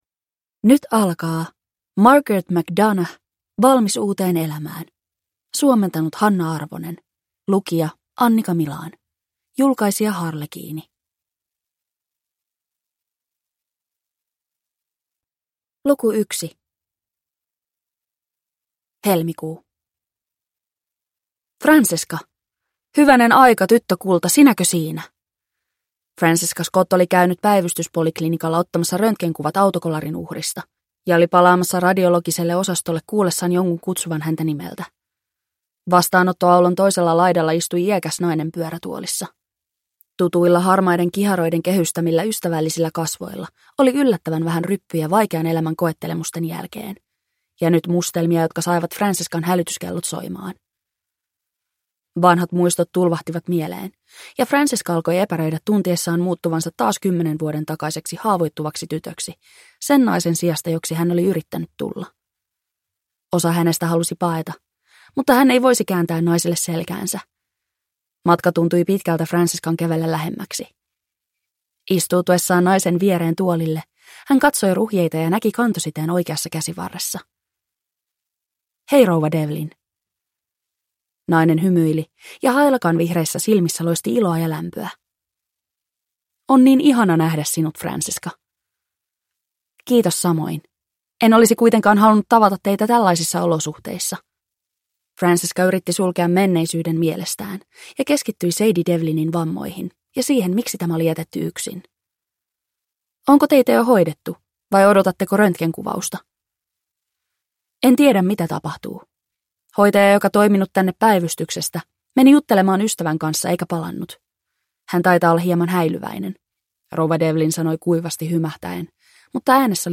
Valmis uuteen elämään (ljudbok) av Margaret McDonagh